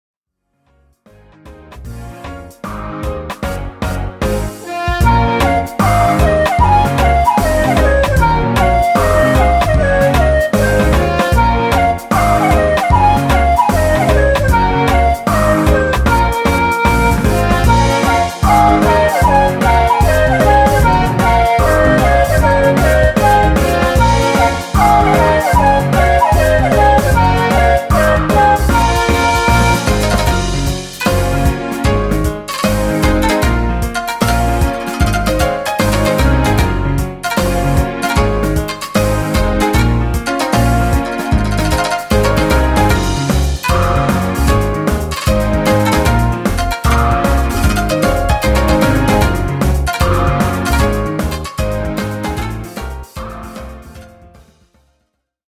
東方風自作曲
できるだけ楽しい感じの雰囲気にしてみました。